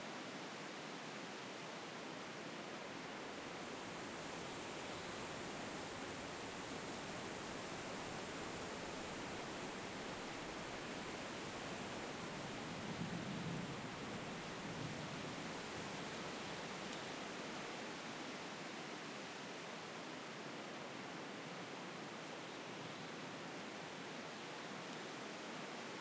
leaves.ogg